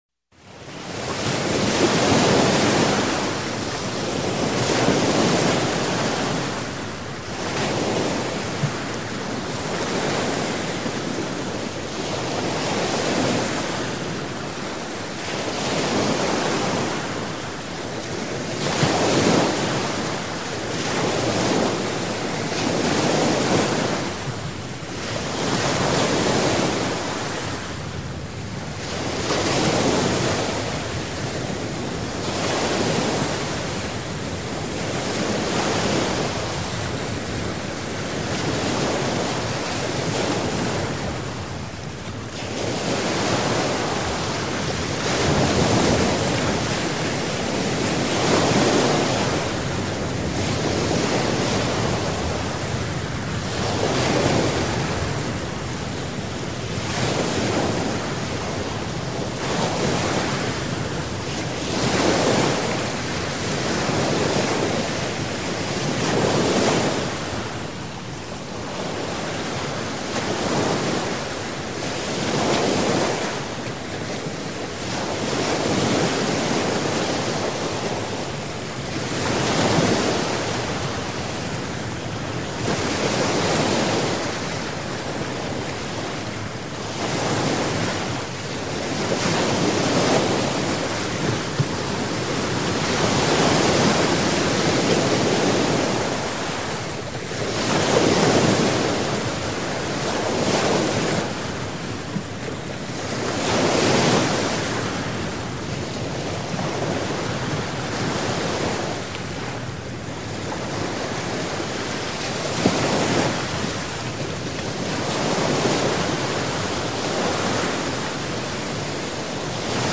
Звуки моря, океана